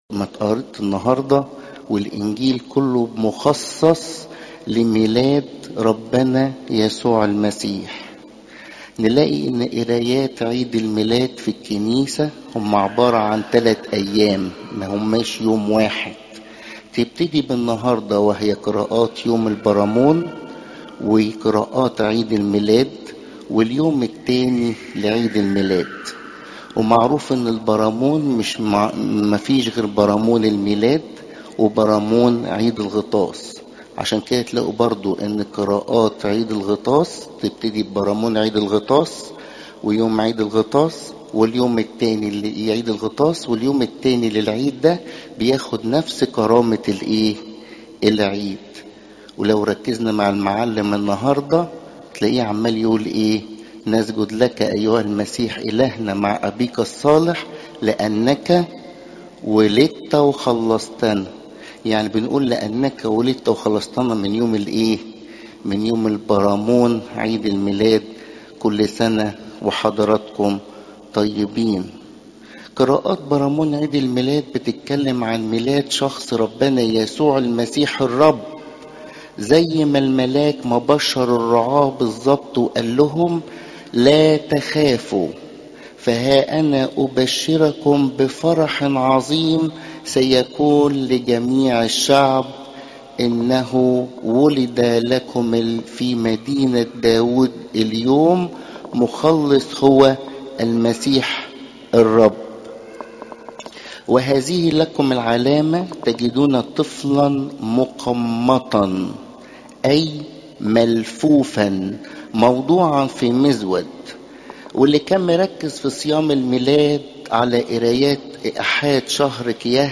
تفاصيل العظة